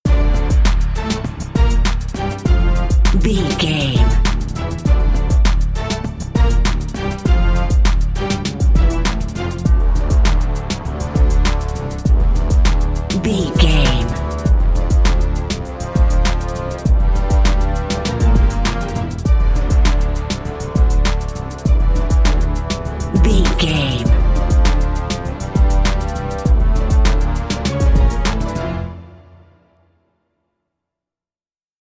Aeolian/Minor
strings
drums
brass
drum machine
orchestra
hip hop
soul
Funk
acid jazz
energetic
cheerful
bouncy
Triumphant
funky
aggressive